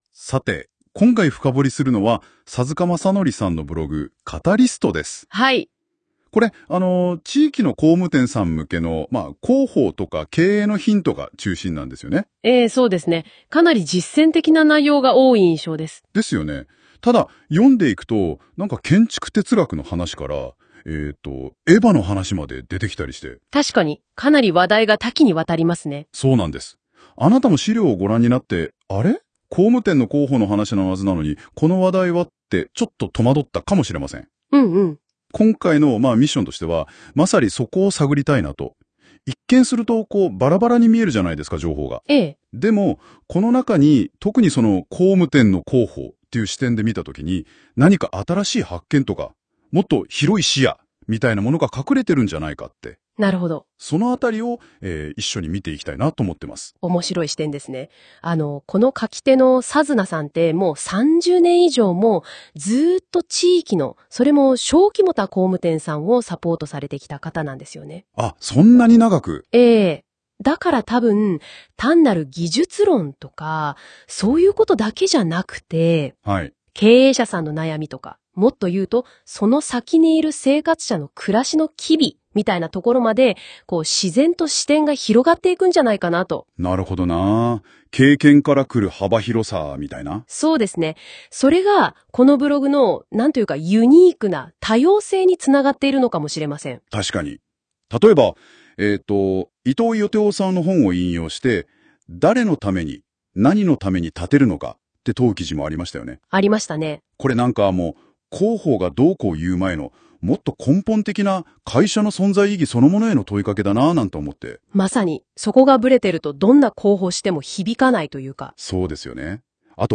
GoogleのNotebookLMの音声概要が日本語に対応したので試してみました。
僕のブログについて二人が語る 音声番組です。